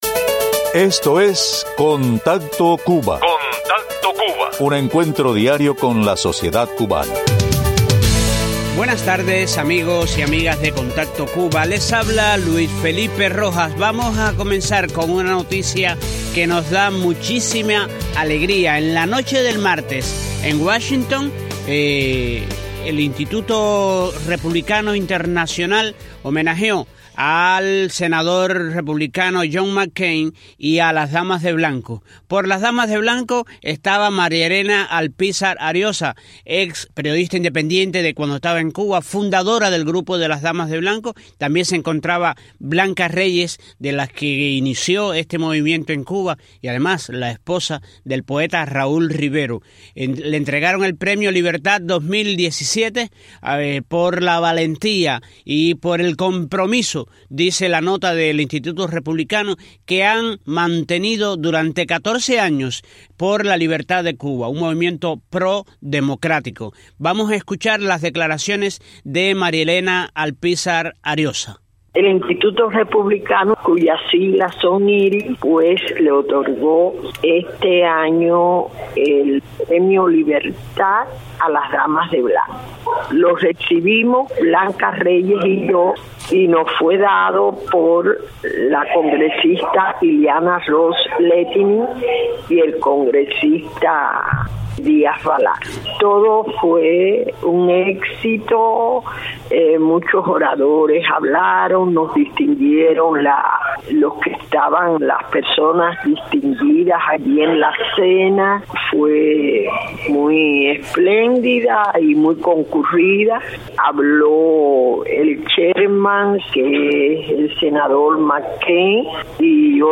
El Instituto Republicano Internacional honró a las opositoras cubanas por su compromiso con la libertad de Cuba. Entrevistas